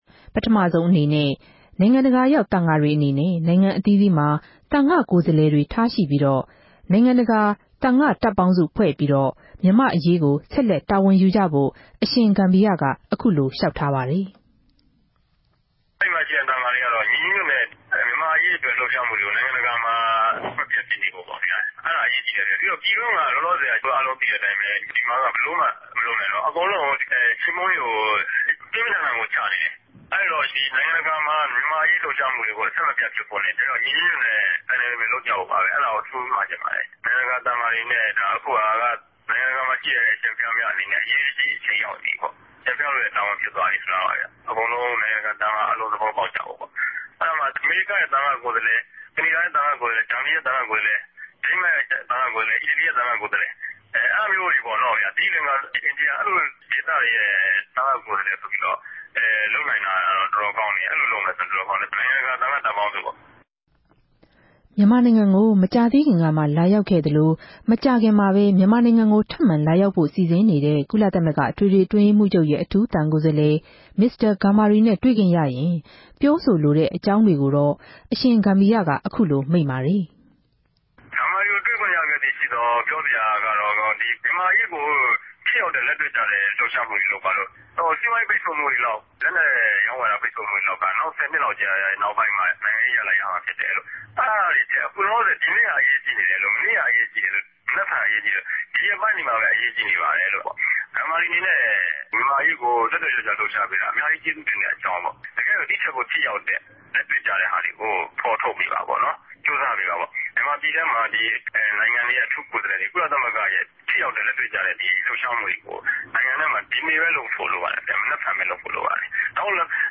ူမန်မာိံိုင်ငံအတြင်းက သံဃာ့လြပ်ရြားမြ ဦးဆောင် ရဟန်းတပၝးူဖစ်သူ အရြင်ဂမႝီရက ကုလသမဂ္ဂ အထူးကိုယ်စားလြယ် မင်္စတာဂမ်ဘာရီကို မြာုကားလိုတဲ့အခဵက်တြေ၊ ိံိုင်ငံတကာလူထုကို မြာုကားခဵက်တြေ၊ အမေရိကန်သမတကို မြာုကားခဵက်တေကြို ရြောင်တိမ်းနေတဲ့ တနေရာကနေ မိန်ႛုကားသြားပၝတယ်။